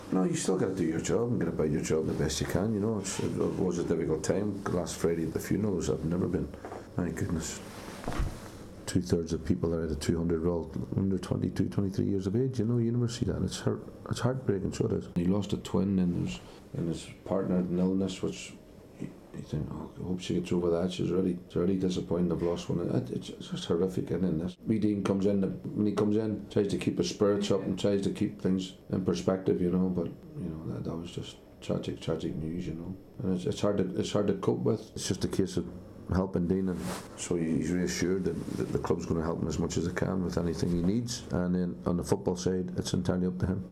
Blue Brazil manager Jimmy Nicholl says it's been hard for everyone to carry on as normal: